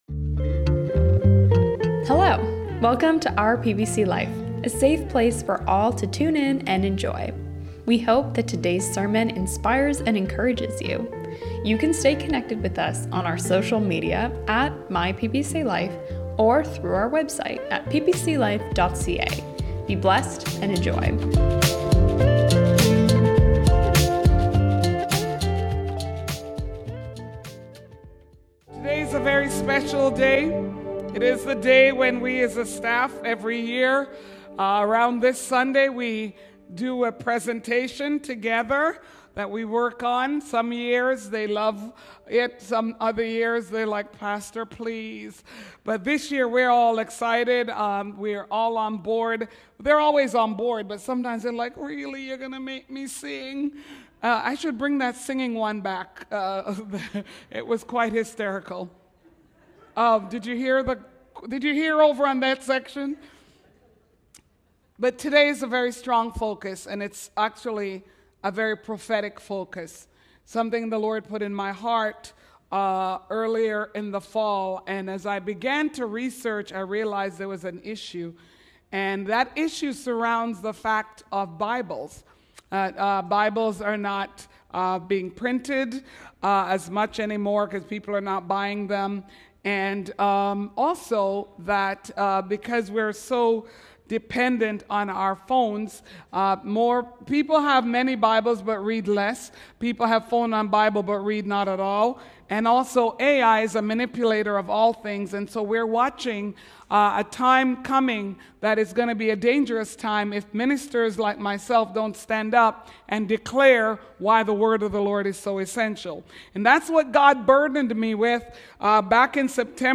Today, we continue our annual tradition where the PPC Staff shares a special Christmas presentation!